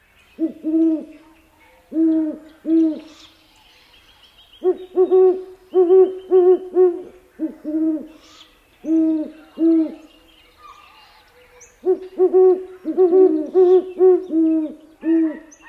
巨角猫头鹰叫声